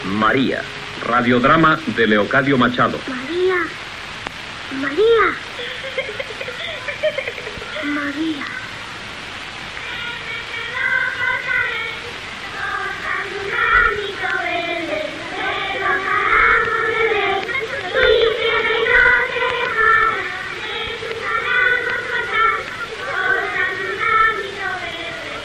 Careta del "radiodrama" radiofònic
Ficció